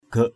/ɡ͡ɣəʔ/ 1.